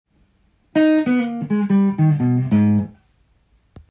G7を例にフレーズを作ってみました。
⑤はオルタードスケールを２弦から弾く弦に帰った雰囲気の
実は、Ｇ７の代理コードのＢＭ７（♯５）
を想定したフレーズになっているという奥深いものです。